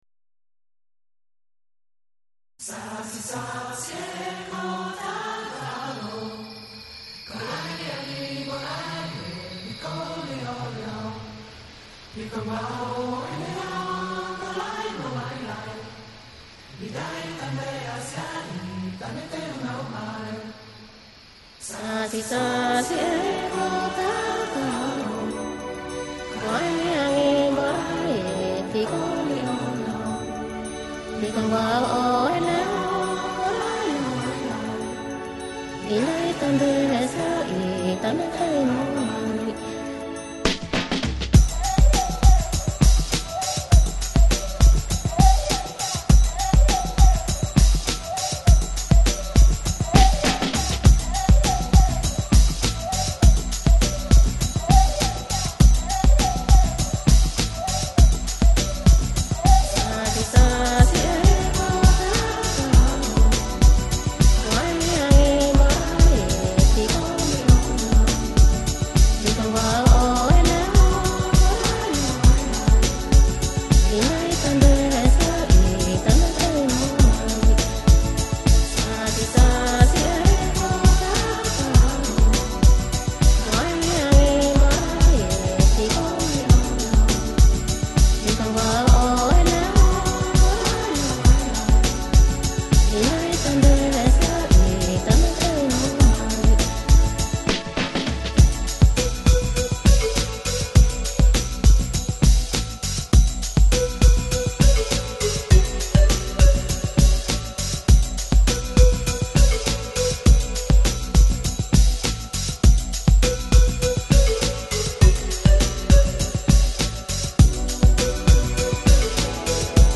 Жанр: Lounge, Chill Out, Electronic